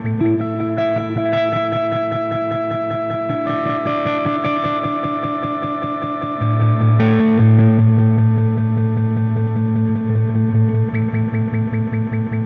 Spaced Out Knoll Electric Guitar 02.wav